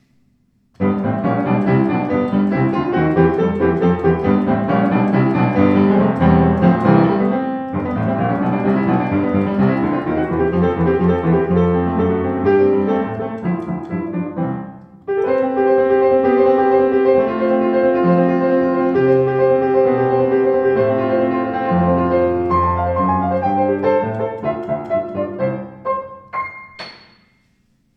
Warmer, ausgewogener Klang mit satten Bässen.